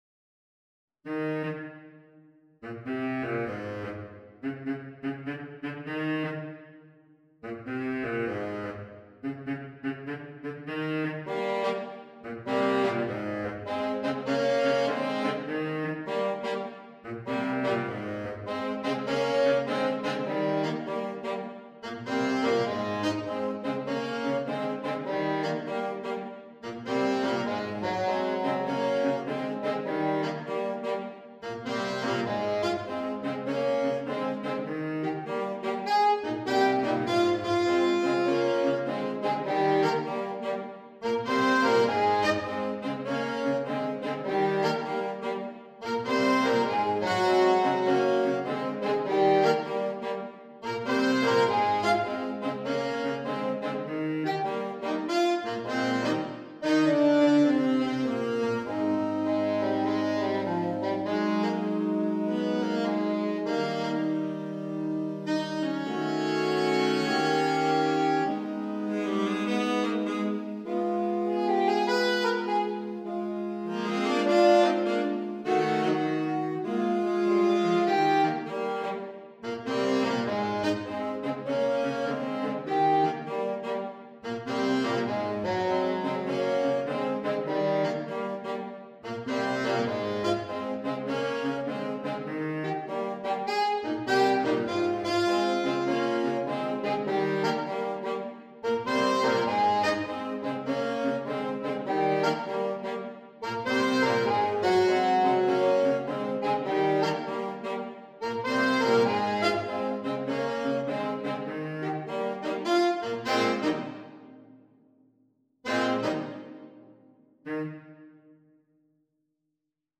• Part 1: Alto Saxophone
• Part 4 Tenor or baritone Saxophone